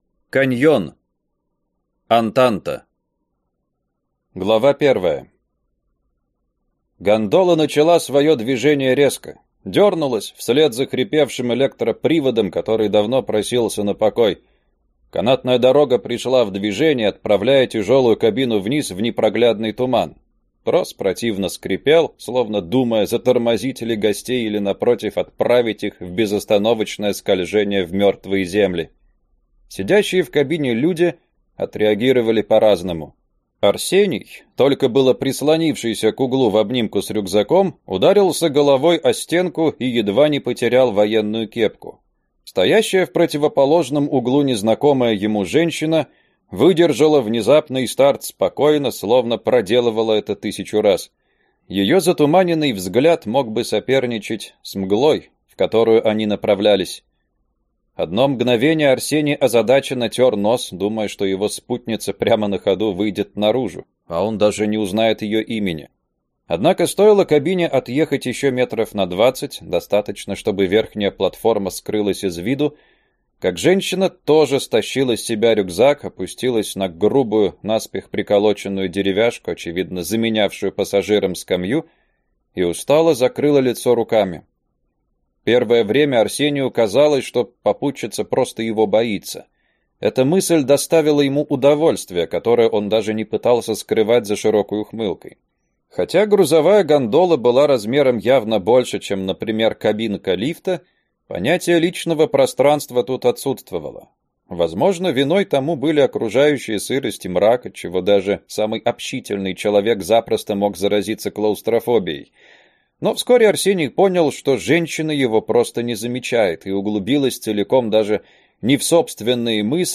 Аудиокнига Антанта | Библиотека аудиокниг
Прослушать и бесплатно скачать фрагмент аудиокниги